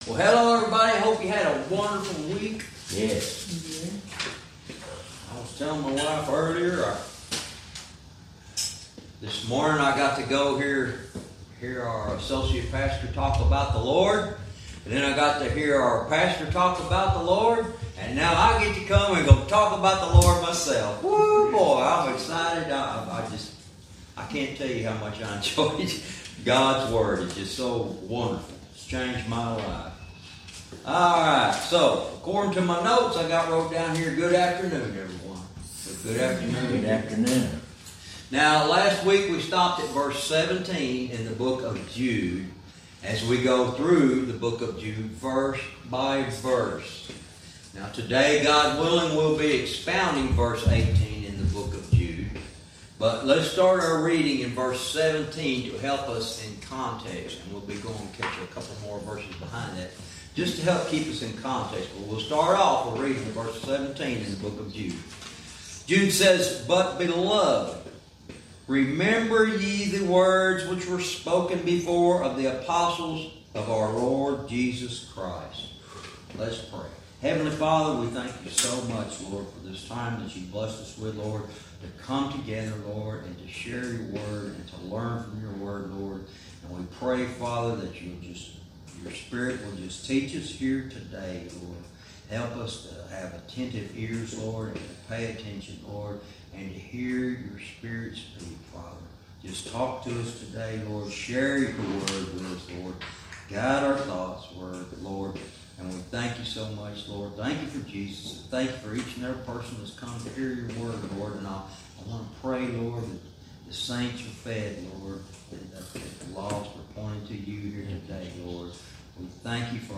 Verse by verse teaching - Jude lesson 77 verse 18